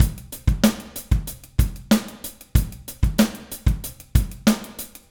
Trem Trance Drums 01.wav